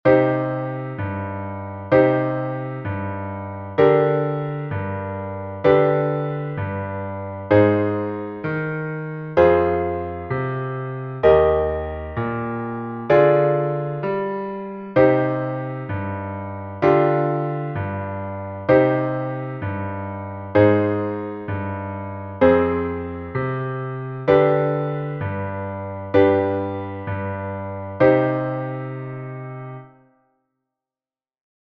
Só acompañamento:
entonacionpiano8,3-Piano.mp3